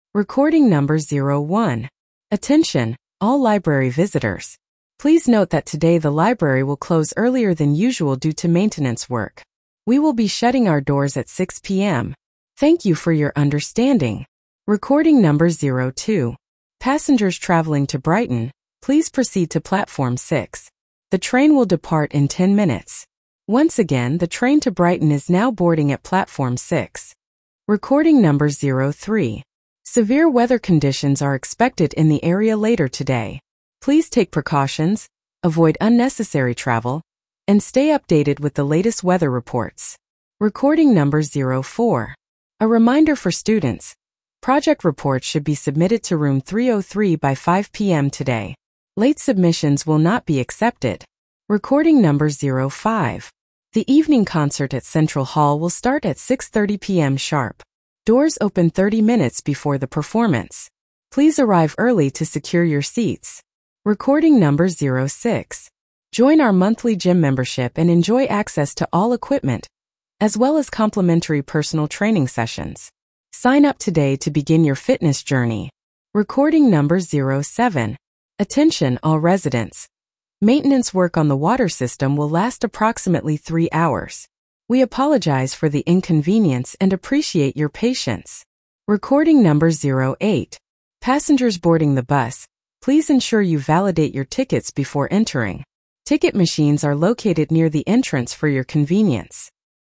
You will hear eight short announcements or instructions.